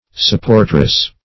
Search Result for " supportress" : The Collaborative International Dictionary of English v.0.48: Supportress \Sup*port"ress\, n. A female supporter.